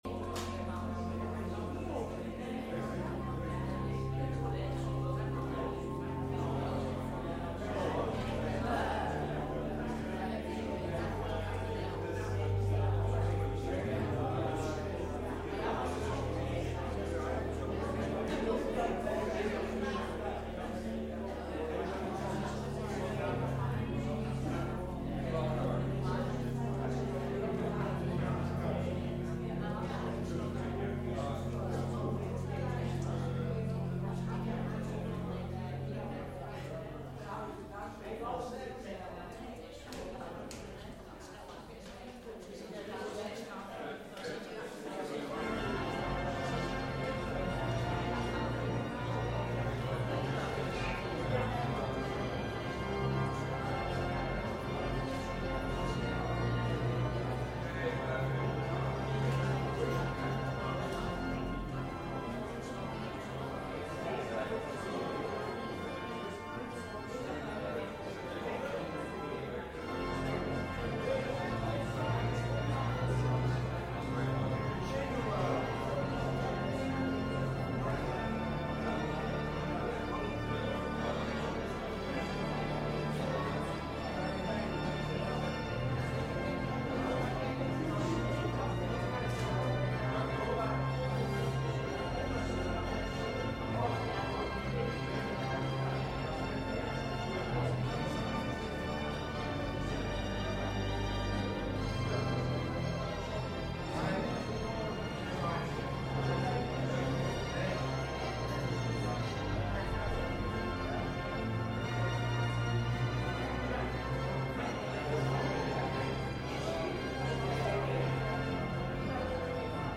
Diensten beluisteren
Elke zondag om 10.00 uur komt de gemeente samen in een kerkdienst.
Tijdens de samenkomsten is er veel aandacht voor muziek, maar ook voor het lezen van Gods woord en het overdenken hiervan. We zingen voornamelijk uit Opwekking en de Johannes de Heer bundel.